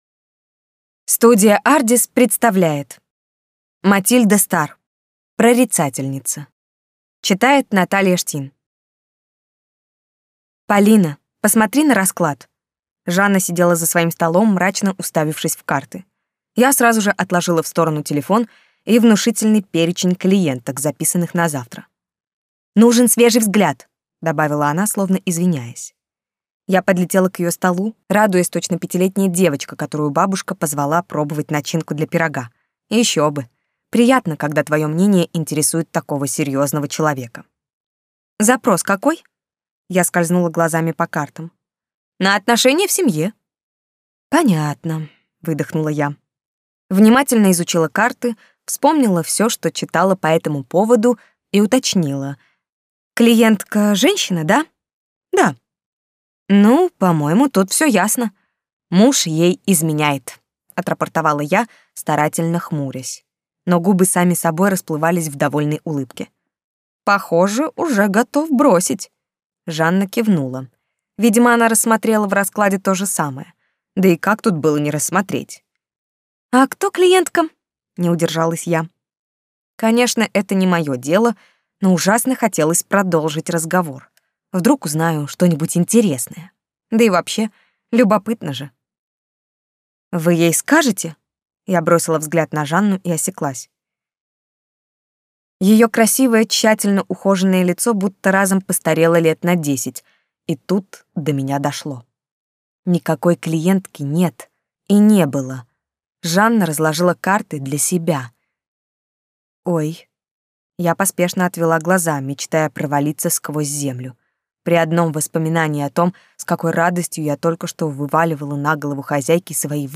Аудиокнига Прорицательница. Академия мертвых душ | Библиотека аудиокниг